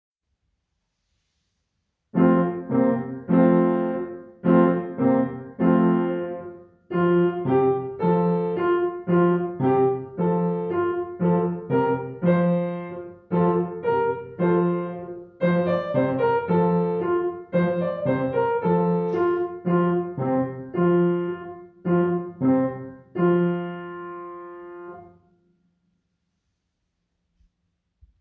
Klavierbegleitung „Bruder Jakob“Herunterladen